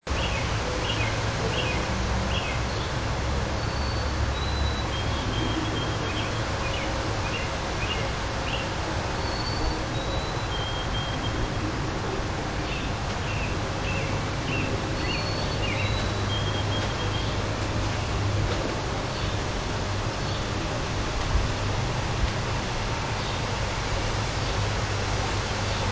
This bird has been singing around the house all day and it’s driving me nuts.
In this recording (not the best because he was a little further away when I recorded it) you will hear two birds.  One is the robin … and then the high pitched squeaky one is the culprit.
Birdsong.mp3